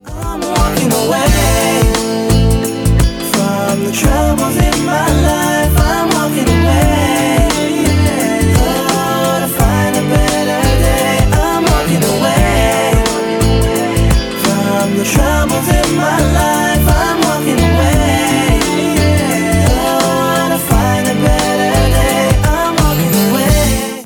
• Качество: 256, Stereo
позитивные
красивые
мелодичные